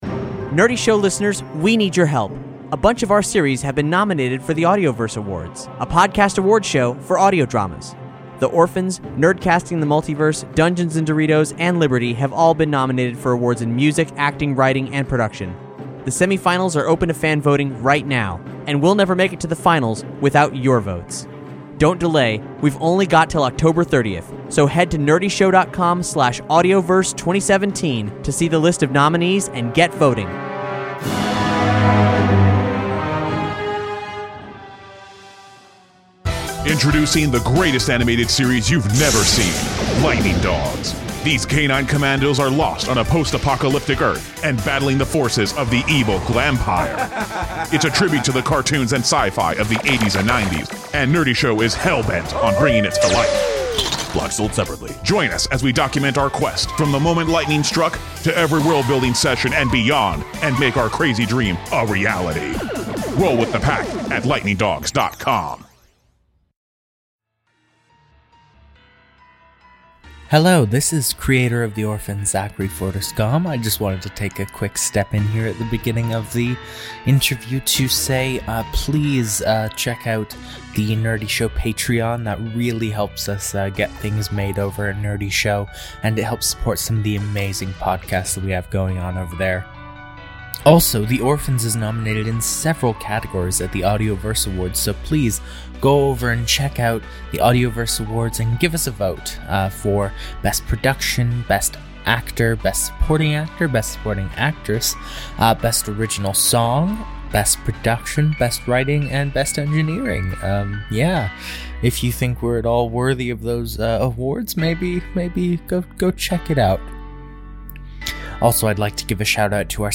The Orphans: Facility – Cast Interview